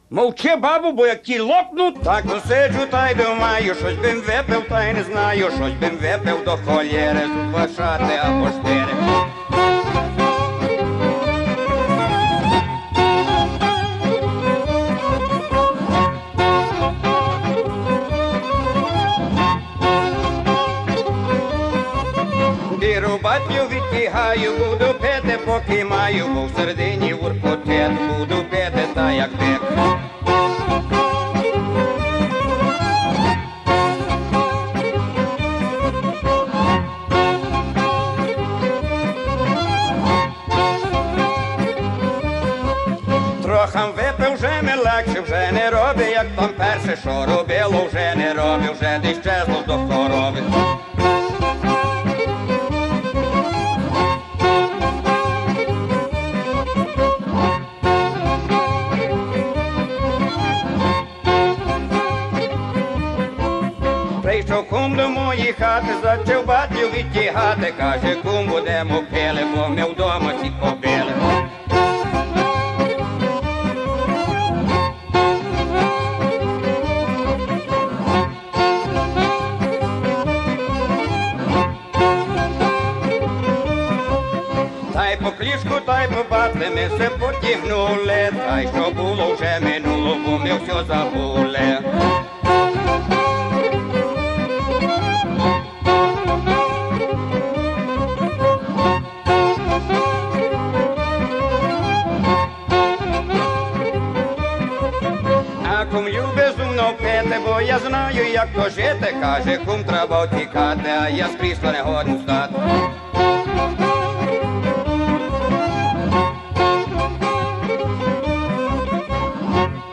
Comments: Another great album with fantastic production.